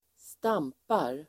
Uttal: [²st'am:par]